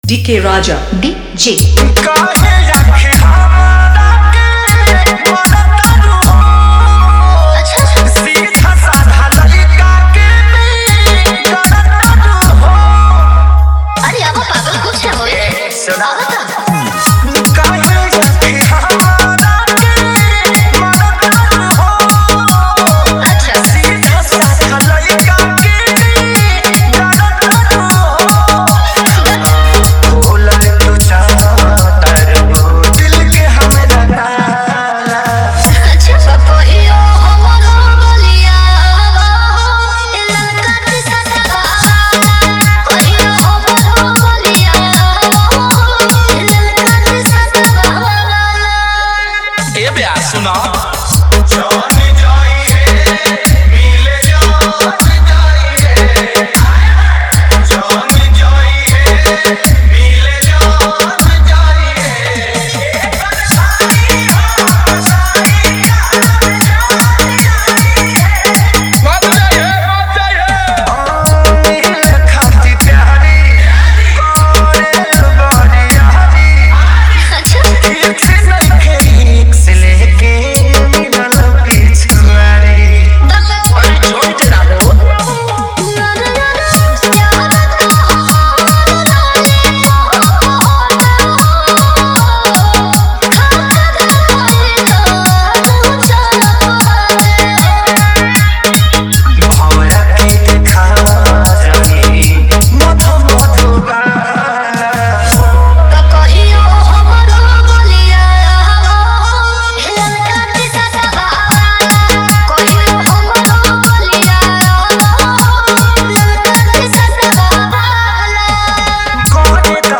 New Bhojpuri Dj Remix